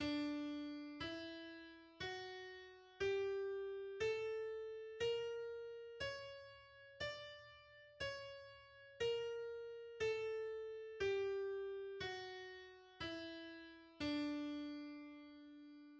D major is a major scale based on D, consisting of the pitches D, E, F, G, A, B, and C. Its key signature has two sharps.
The D harmonic major and melodic major scales are: